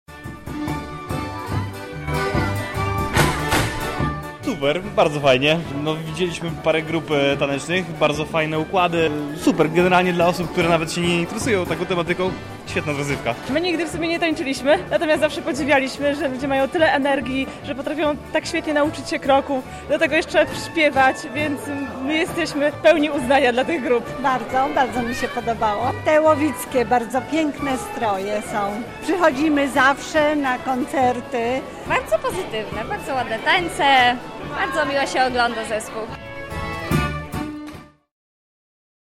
Za nami Koncert Debiutowy Zespołu Tańca Ludowego UMCS.
Całe wydarzenie miało miejsce w Chatce Żaka.